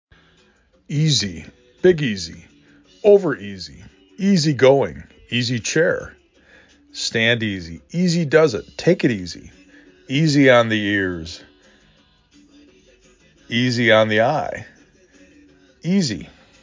E z E